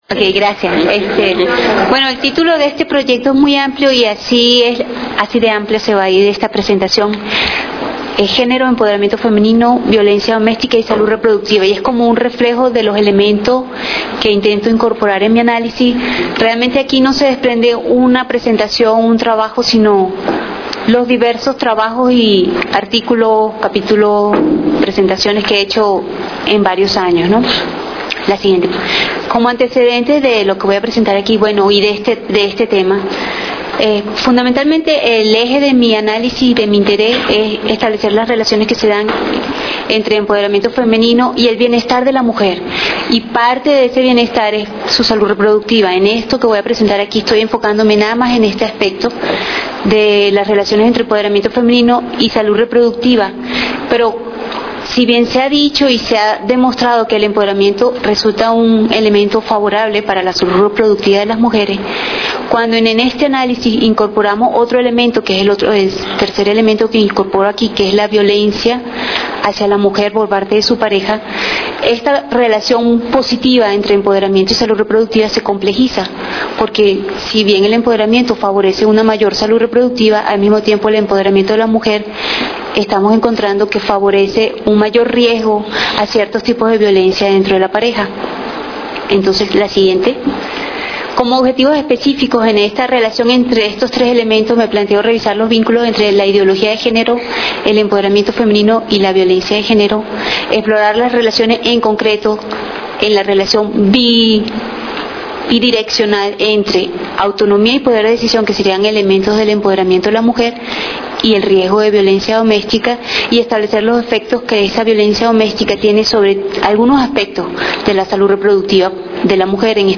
ponencia
en la primera jornada del Primer Encuentro de Investigadores del CRIM que se llevó acabo el 11 de junio de 2007.